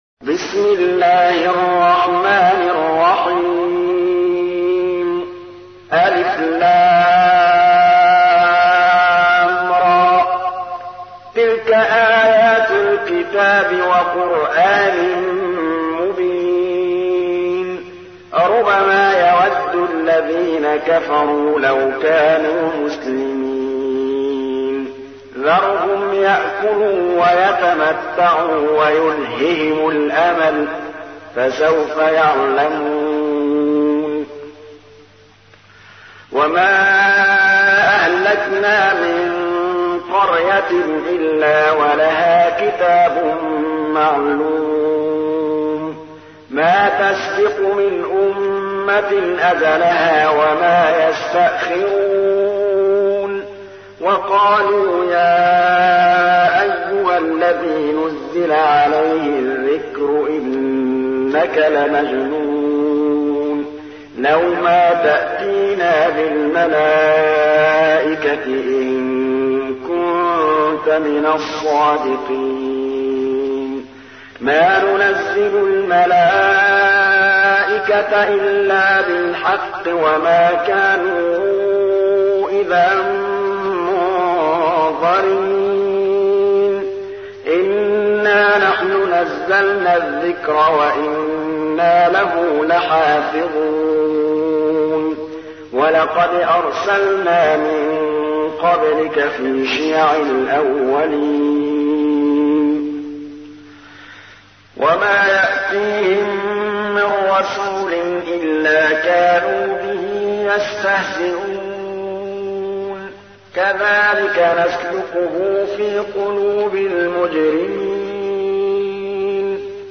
تحميل : 15. سورة الحجر / القارئ محمود الطبلاوي / القرآن الكريم / موقع يا حسين